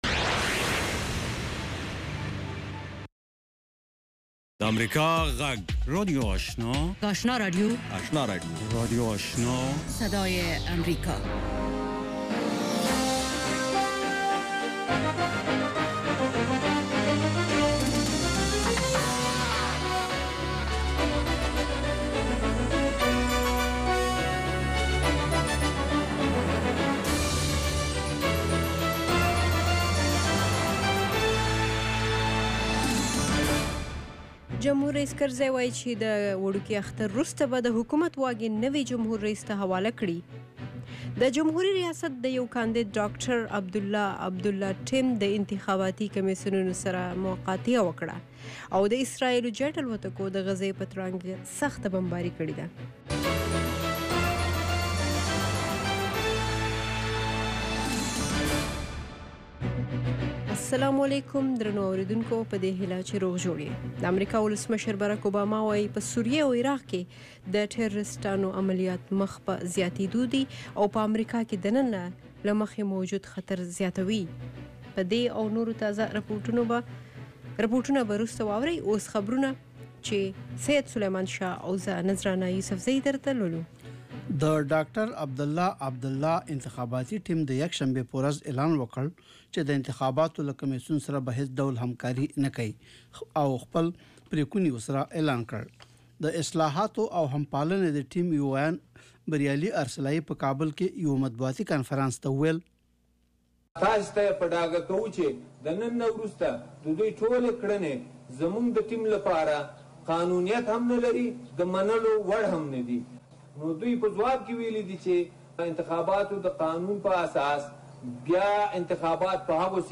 دویمه سهارنۍ خبري خپرونه